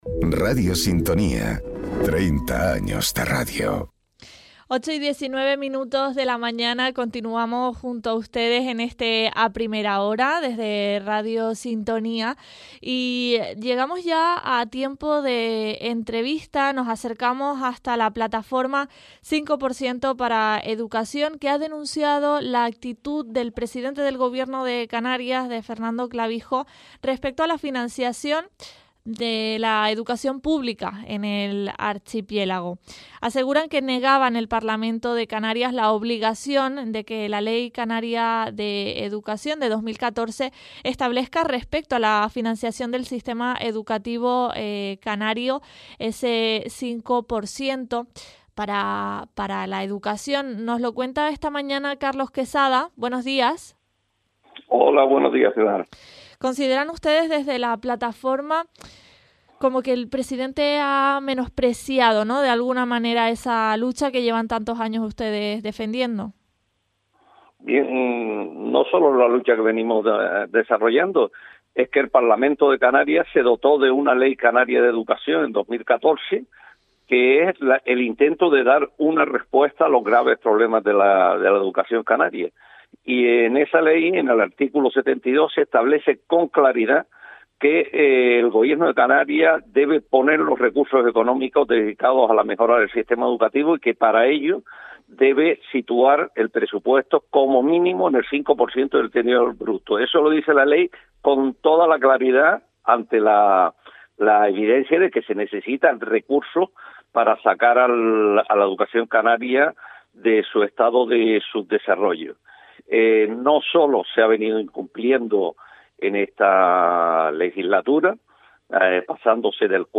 Entrevistas A Primera Hora